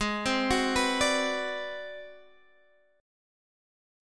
Ab7#9b5 chord